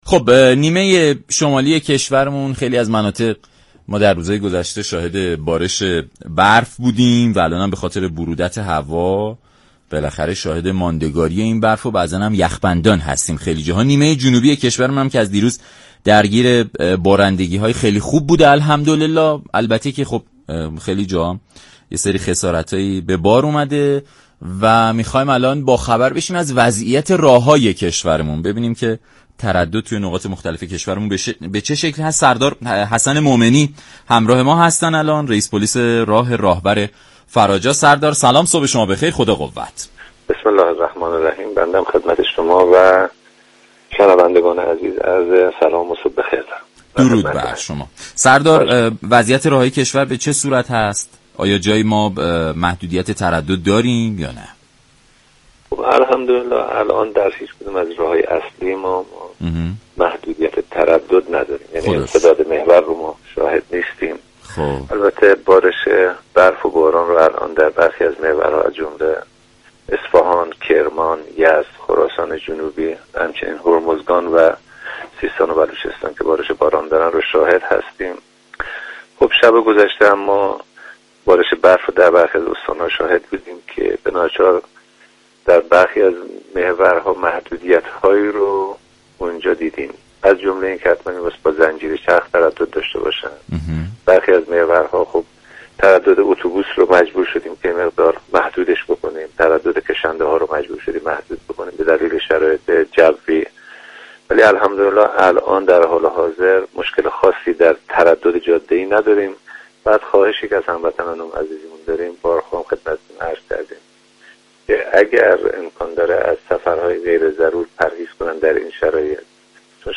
به گزارش شبكه رادیویی ایران، سردار حسن مومنی رییس پلیس راه راهور فراجا در برنامه «سلام صبح بخیر» درباره وضعیت راه های كشور گفت: علی رغم برودت هوا طی روزهای گذشته، جاده های كشو در حال حاضر، هیچگونه محدودیتی در تردد ندارند.